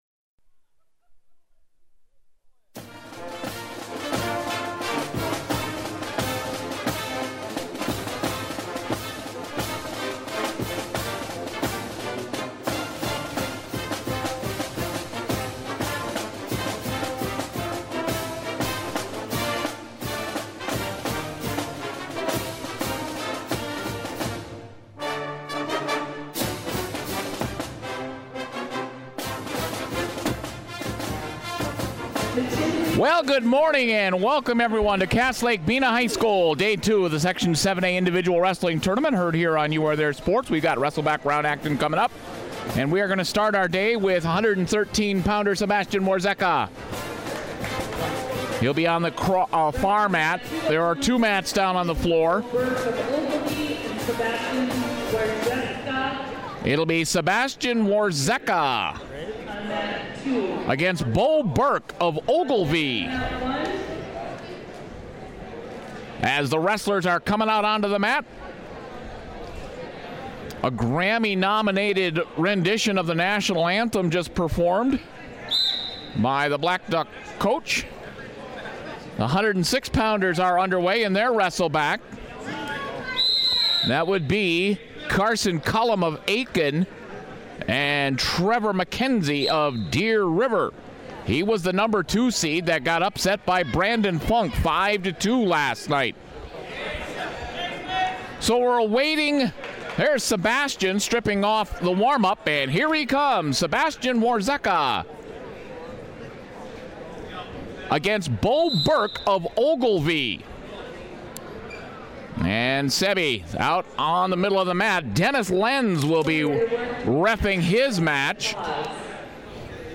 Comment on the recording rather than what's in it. through the wrestleback rounds at the 2017 Section 7A Individual Tournament at Cass Lake-Bena High School.